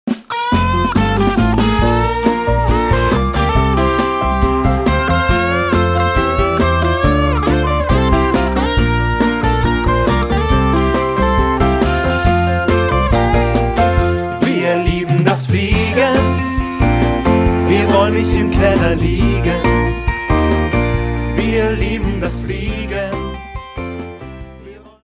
Lach- und Mitmachlieder für Kinder